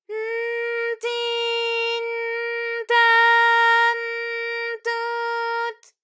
ALYS-DB-001-JPN - First Japanese UTAU vocal library of ALYS.
t_n_ti_n_ta_n_tu_t.wav